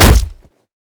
flesh3.wav